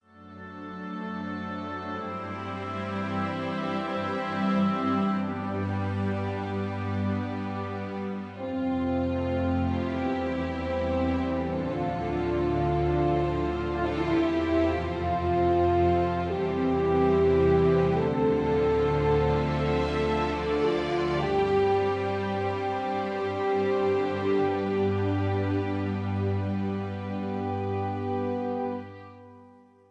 (Key-Am)
Just Plain & Simply "GREAT MUSIC" (No Lyrics).
mp3 backing tracks